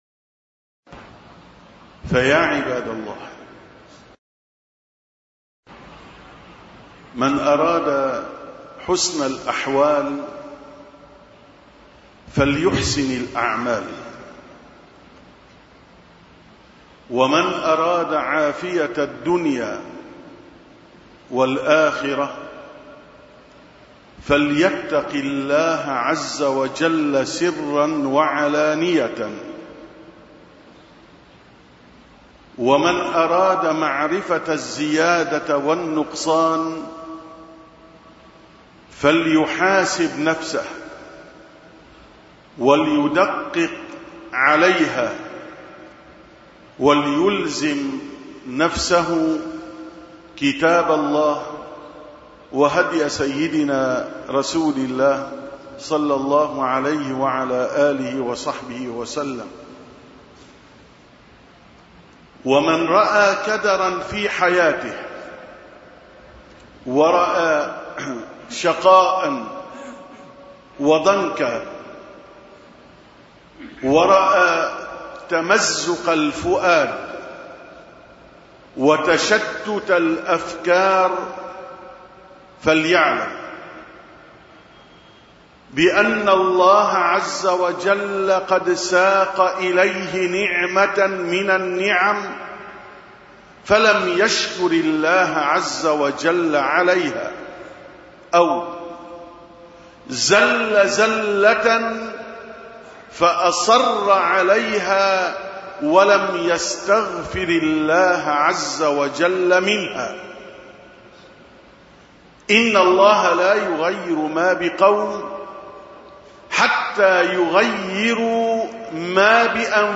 875ـ خطبة الجمعة: العافية مقرونة مع الإقبال على الله تعالى